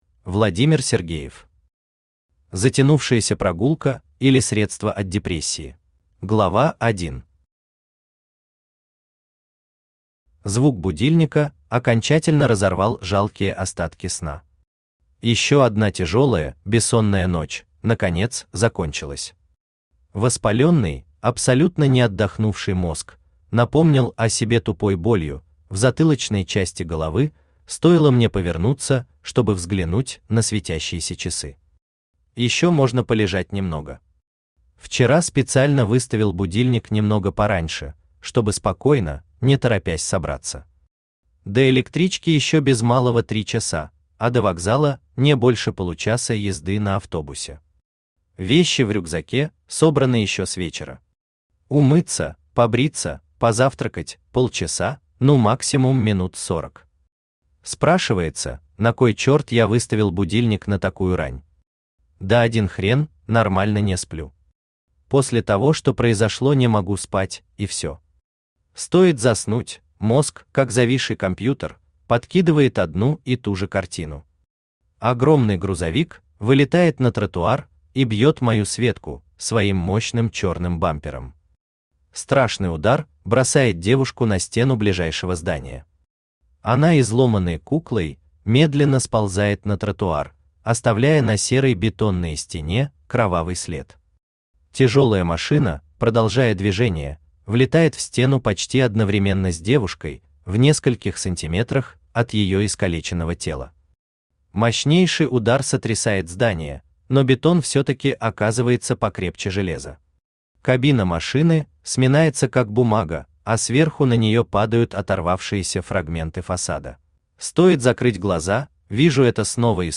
Aудиокнига Затянувшаяся прогулка, или Средство от депрессии Автор Владимир Алексеевич Сергеев Читает аудиокнигу Авточтец ЛитРес.